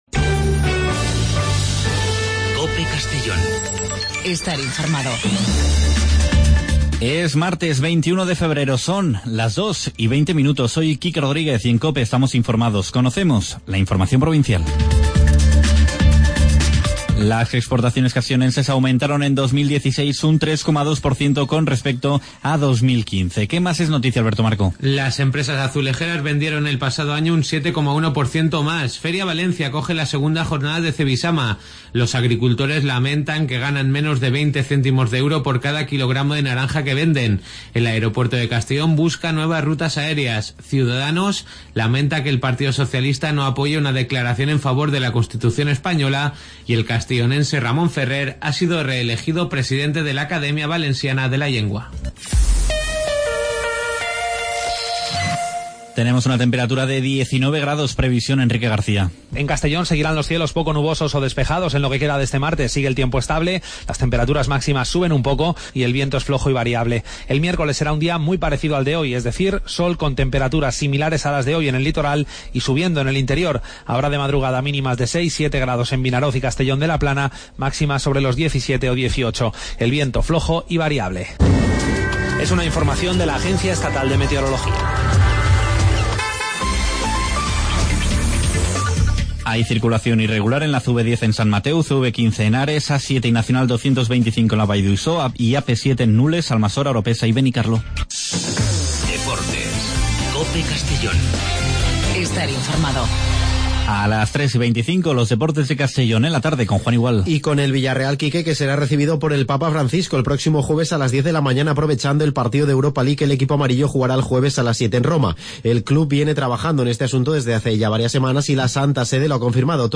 AUDIO: Las noticias del día de 14:20 a 14:30 en Informativo Mediodía COPE en Castellón.